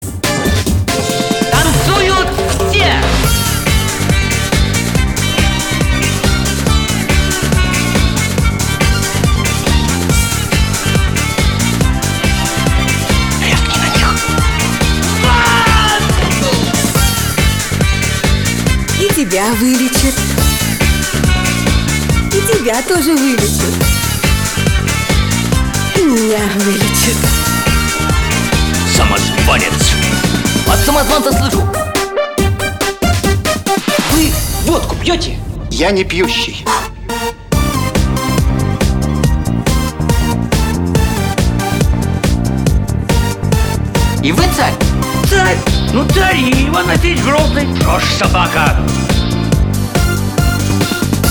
• Качество: 192, Stereo
позитивные
веселые
в небольшой обработке